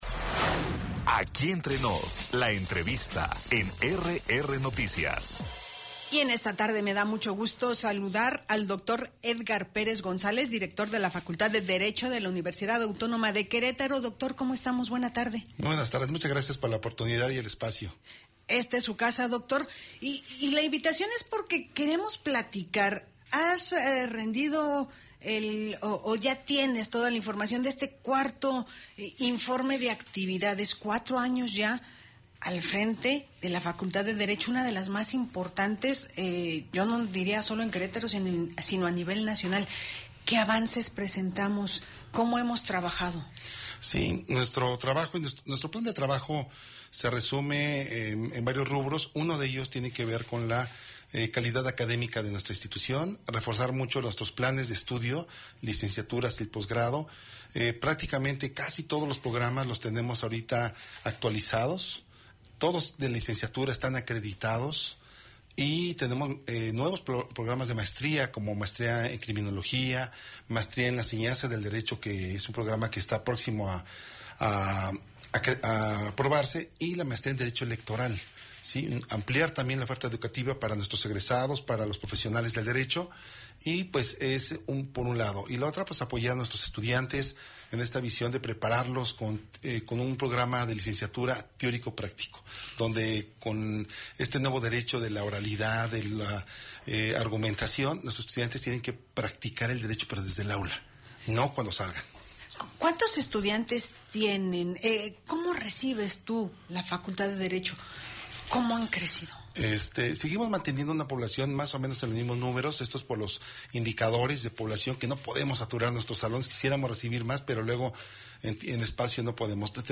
EntrevistasMunicipiosPodcastU2